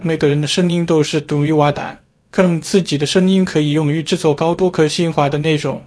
语音克隆的「口音」问题
这个示例是使用 8 分钟 B 站视频和 CJE 模型训练出的。但你可能注意到了明显的断调口音问题，仿佛一个日本人在说中文。
clonevoice01.wav